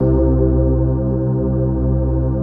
CHRDPAD089-LR.wav